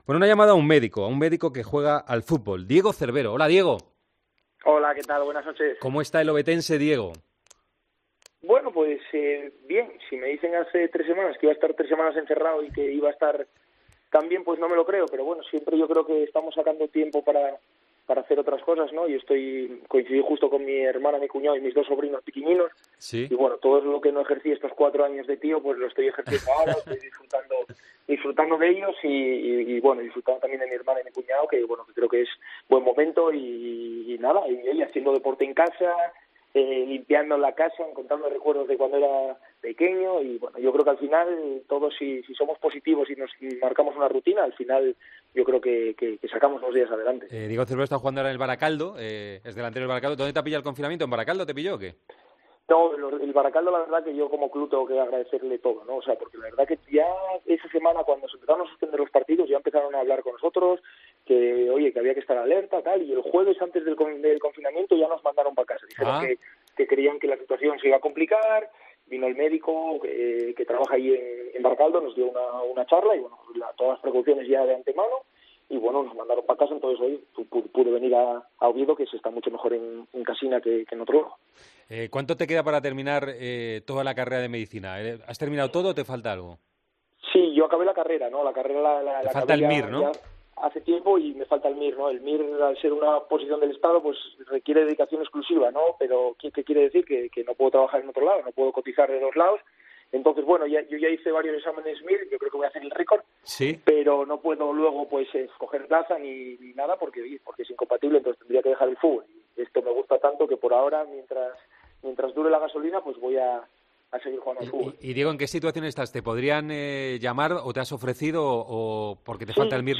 Cambió el balón por la bata cuando tuvo que luchar contra el coronavirus. Nos contó su experiencia en Tiempo de Juego.